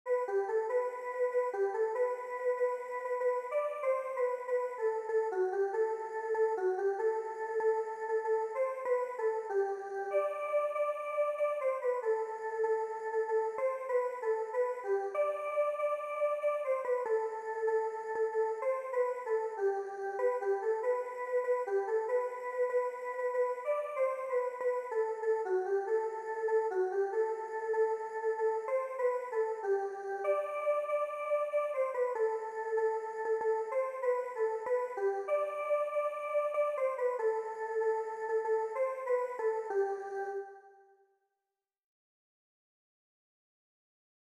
Corpsyphonie,l�association harmonieuse du corps et de l�esprit par la voix - La fille du coupeur de paille (chant rythmique pour triolet)
La m�lodie.
Chant traditionnel